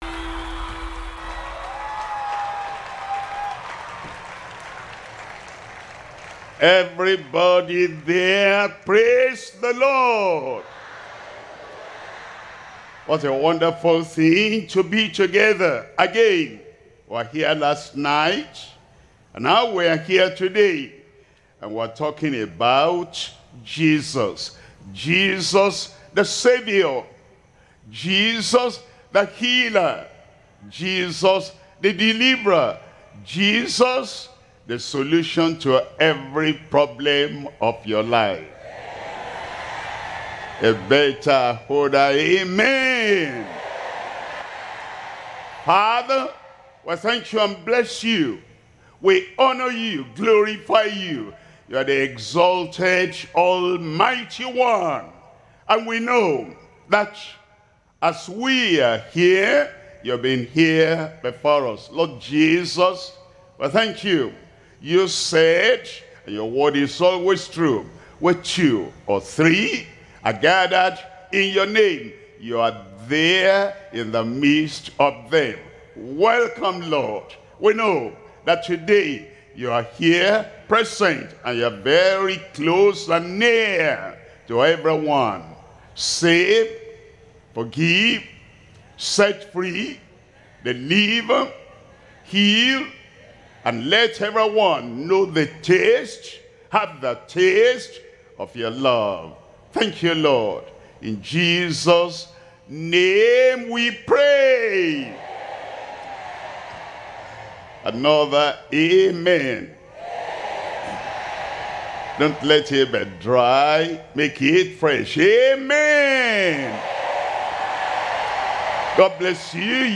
Sermons – Deeper Christian Life Ministry, United Kingdom